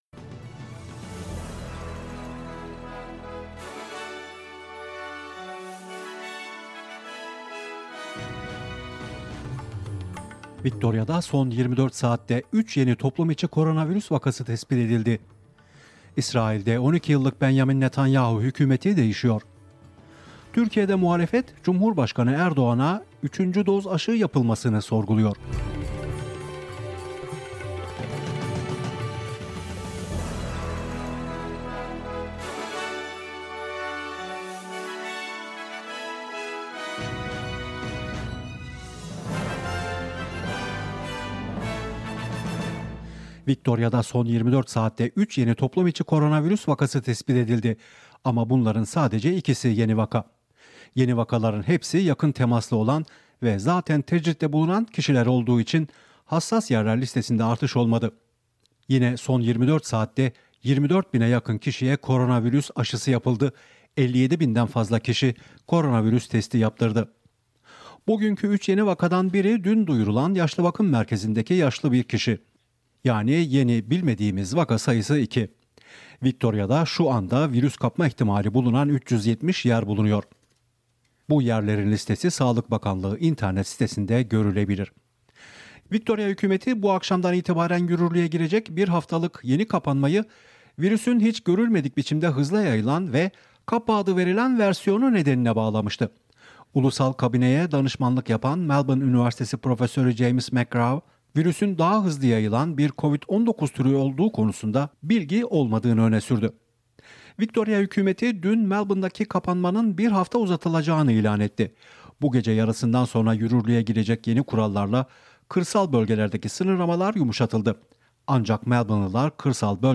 SBS Türkçe Haberler 3 Haziran